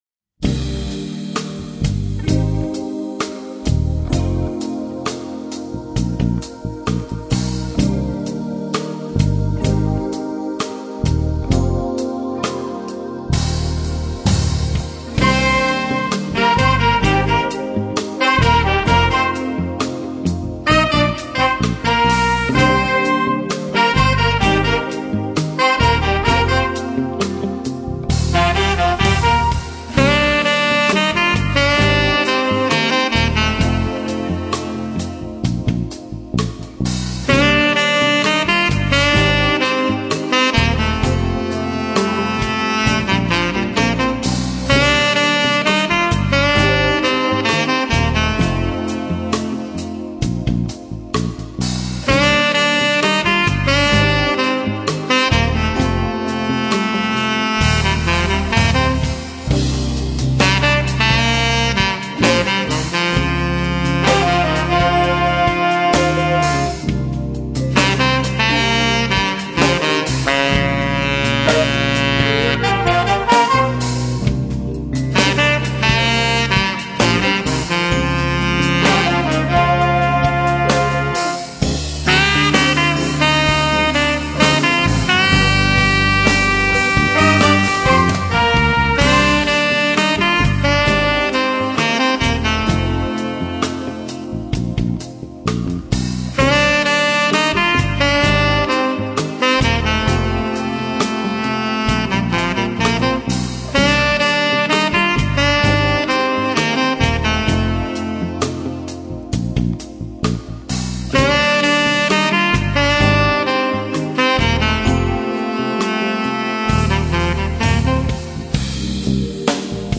Signál  šel z basy do jakéhosi lampového preampu a pak do linky.